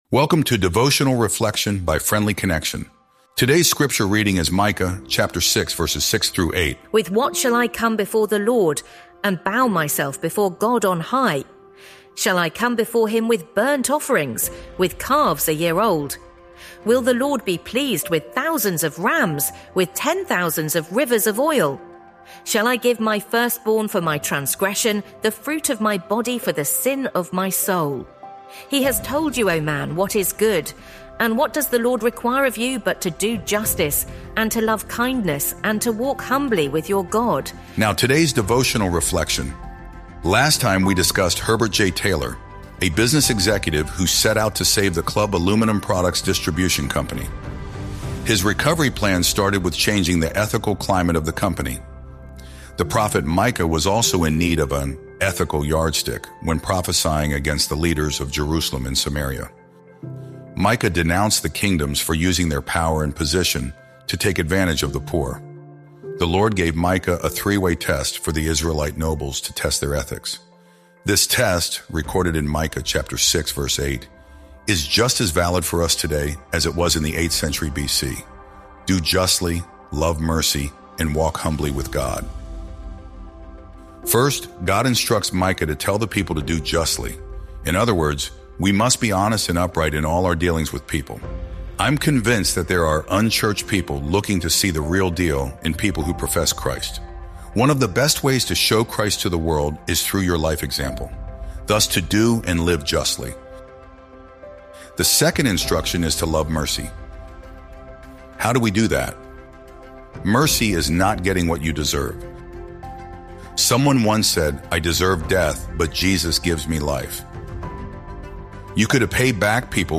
Sermons | Honey Creek New Providence Friends Church